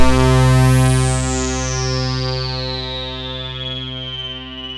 KORG C3  1.wav